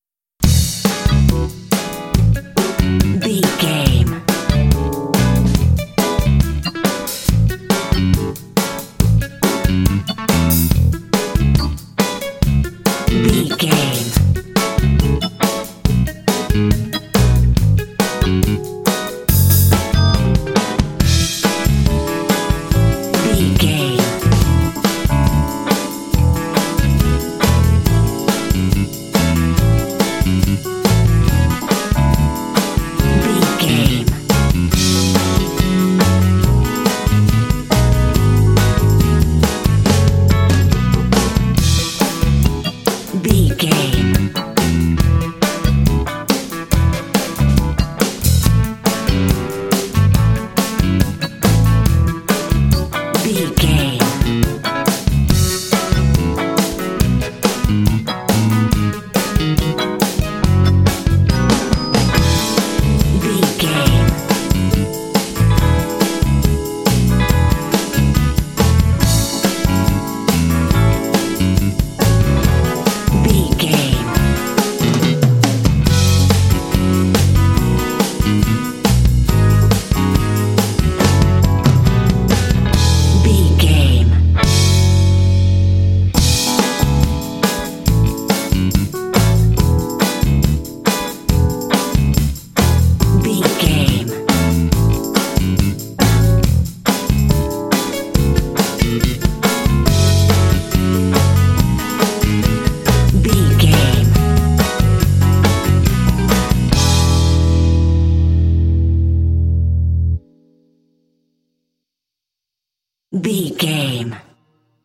Uplifting
Ionian/Major
funky
groovy
electric guitar
bass guitar
drums
electric piano
Funk